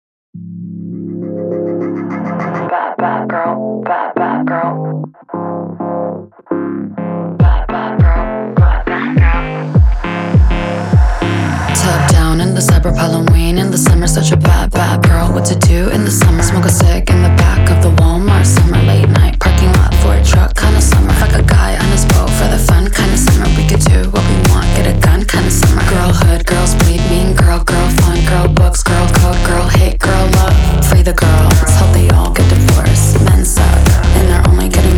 Pop Hip-Hop Rap
Жанр: Хип-Хоп / Рэп / Поп музыка